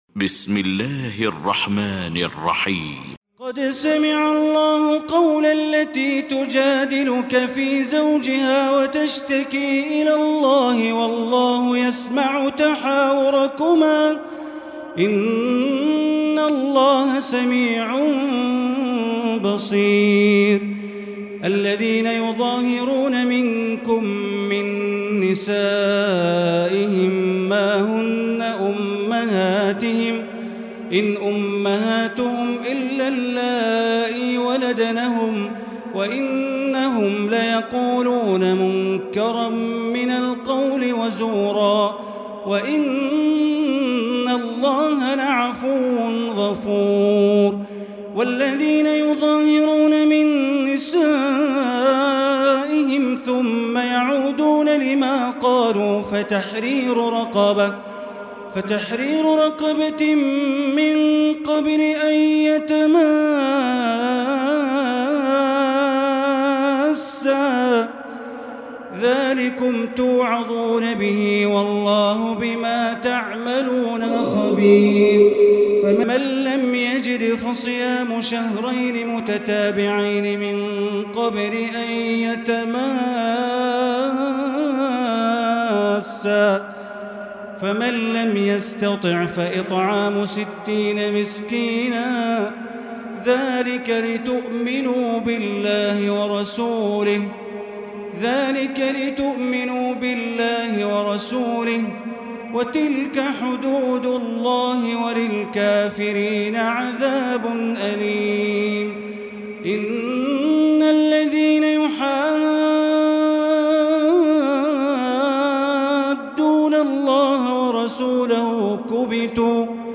المكان: المسجد الحرام الشيخ: معالي الشيخ أ.د. بندر بليلة معالي الشيخ أ.د. بندر بليلة المجادلة